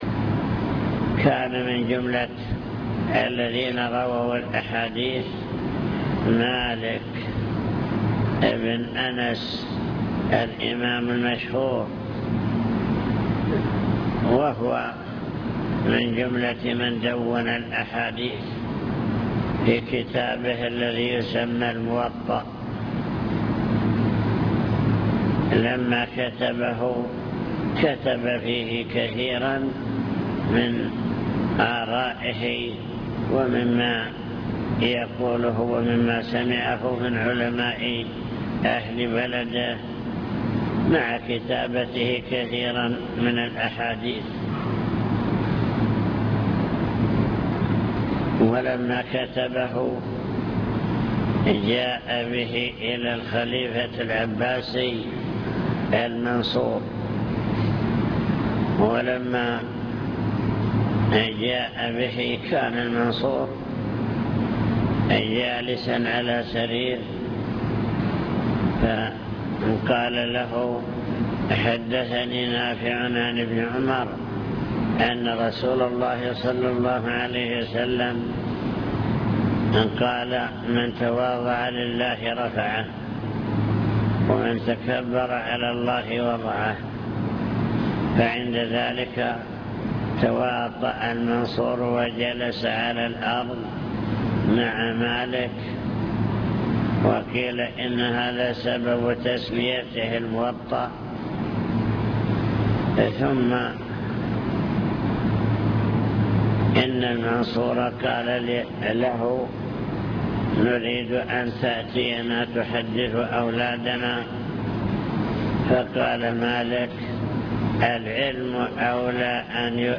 المكتبة الصوتية  تسجيلات - محاضرات ودروس  محاضرات بعنوان: عناية السلف بالحديث الشريف عناية التابعين بالحديث